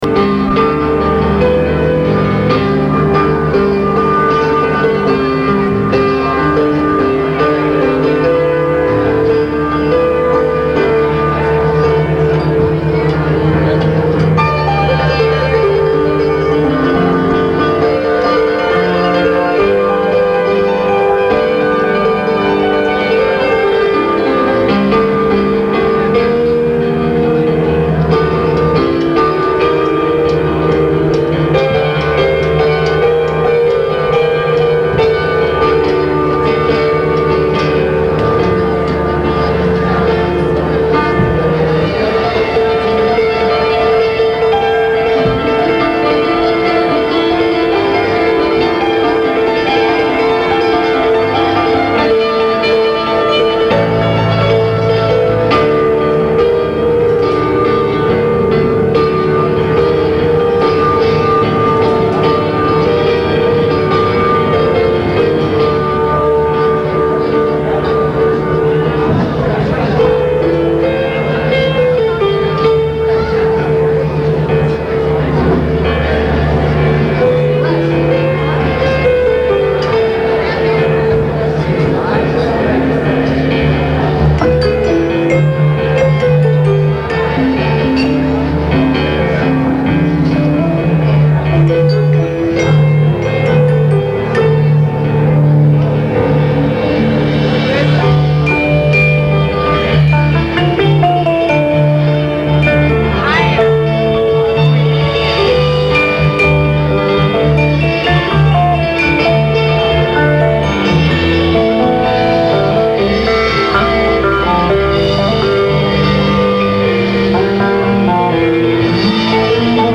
Guitars, Vocals, Glockenspiel
Vocals, Bass, Mellotron
Keys, Sax, Flute, Vocals
Drums, Vocals
live